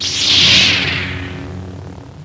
saber5on[1].wav